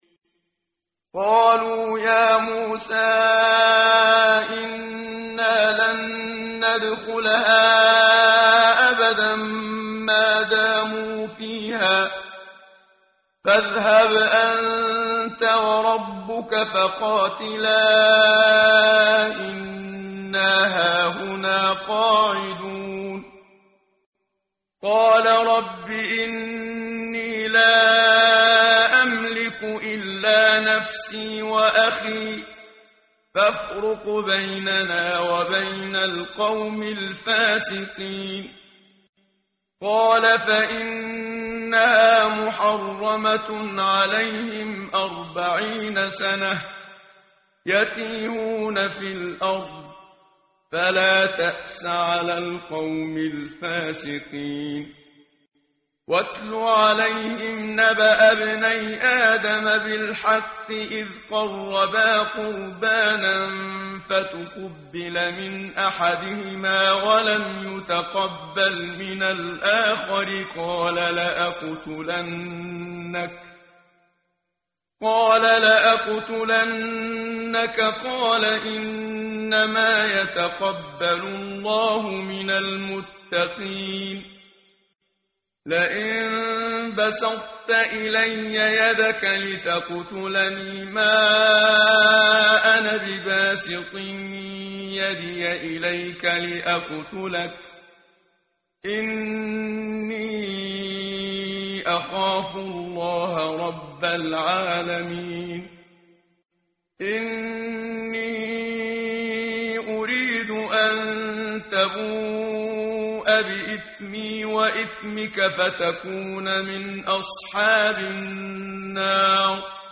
ترتیل صفحه 112 سوره مبارکه المائده (جزء ششم) از سری مجموعه صفحه ای از نور با صدای استاد محمد صدیق منشاوی
quran-menshavi-p112.mp3